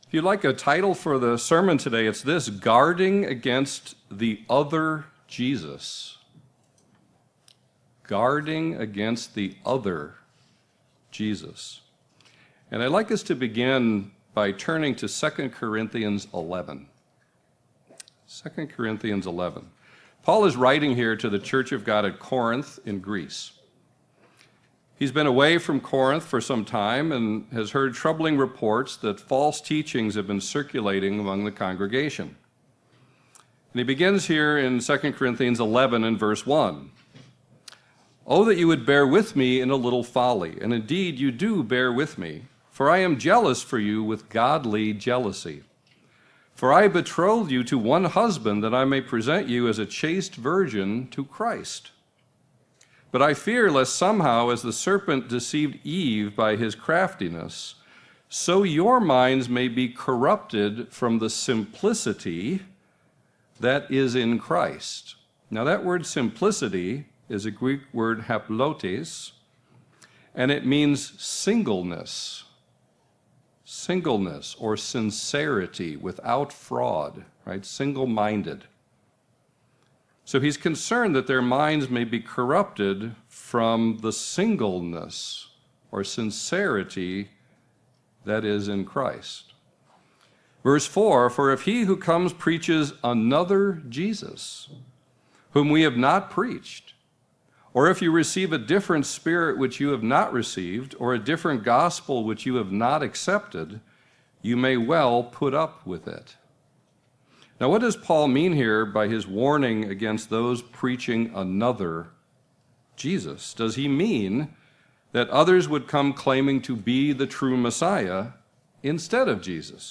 What does Paul mean in 2 Corinthians 11:4 when he warns Christians to beware receiving those preaching another Jesus? This sermon explores the questions of how teachings about "another" Jesus are just as problematic for Christians today as they were in Paul's time. Specifically, how can we, as Christians, guard against being deceived by those preaching this "other" Jesus?